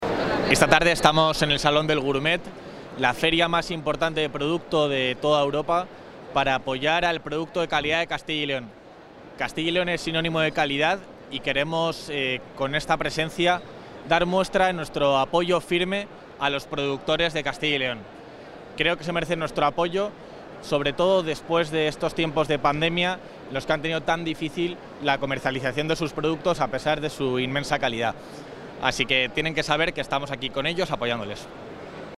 Declaraciones del vicepresidente de la Junta.